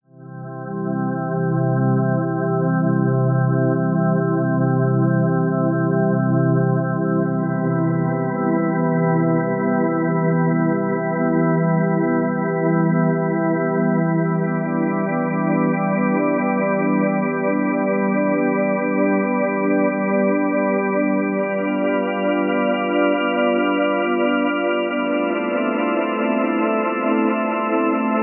描述：史诗般的垫环。
Tag: 120 bpm Trance Loops Pad Loops 2.69 MB wav Key : Unknown